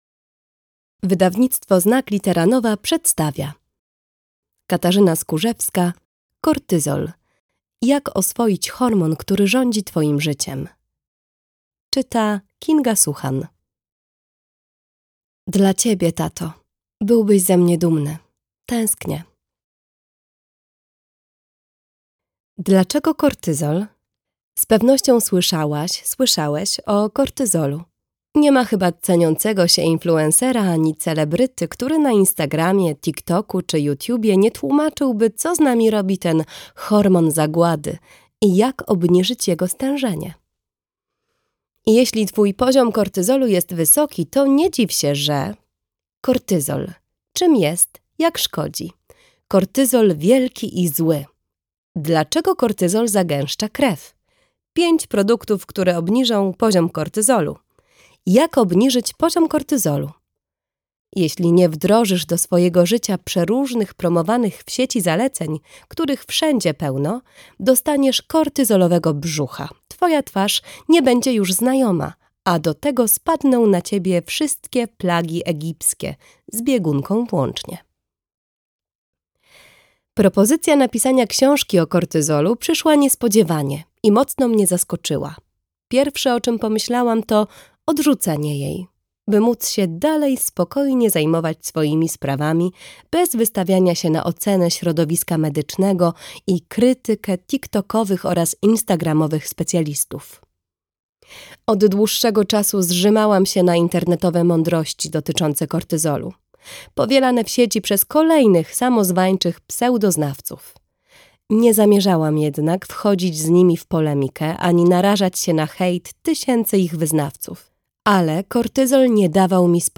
Audiobook Kortyzol.